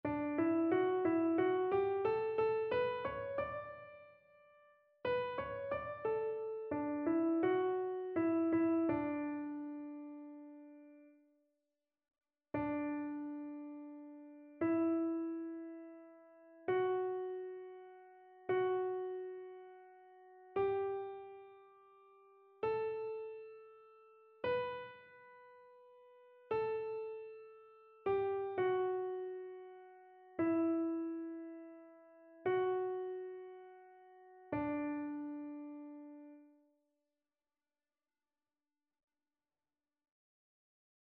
Chœur
annee-b-temps-pascal-6e-dimanche-psaume-97-soprano.mp3